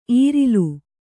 ♪ īrilu